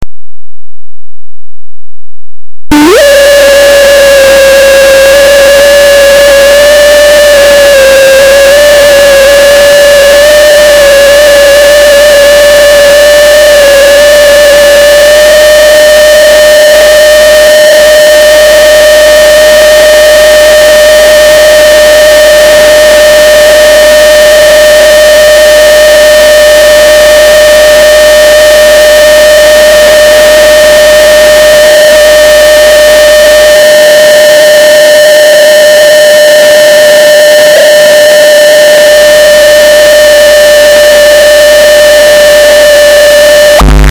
22/05/2005,Campomarzo di Lendinara. Ricevitore ICOM IC-R 100, antenna Yagi.
segnale radio1420.mp3